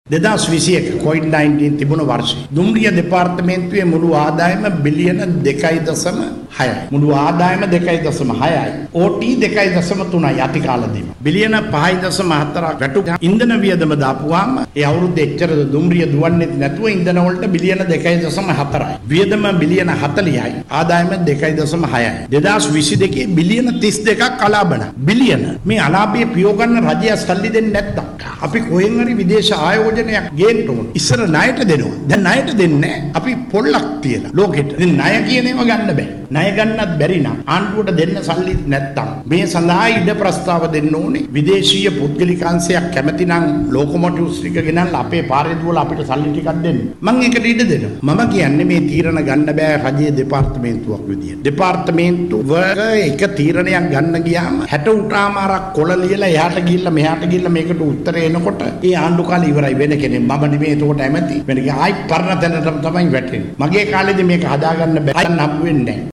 දුම්රිය රියදුරු සංගමේ වාර්ෂික මහා සභා රැස්වීම අමතමිනුයි අමාත්‍යවරයා මෙම අදහස් පළ කළේ.